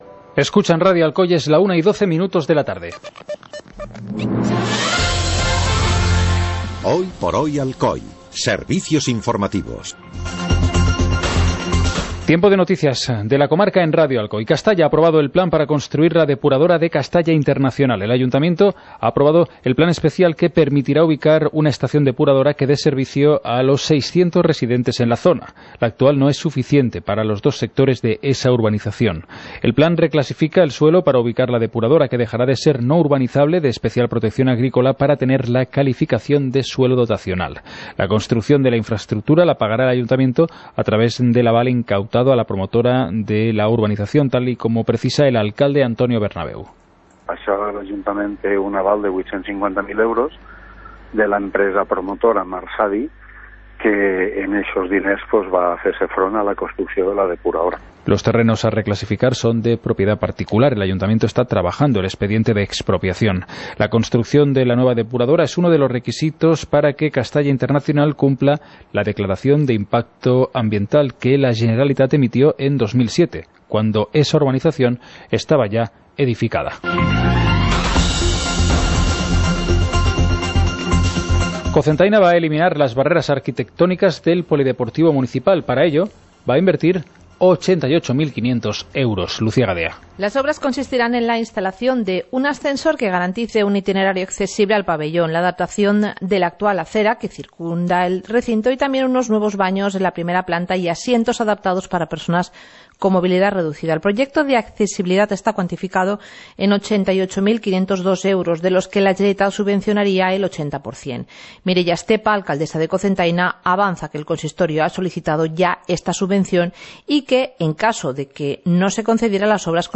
Informativo comarcal - miércoles, 20 de junio de 2018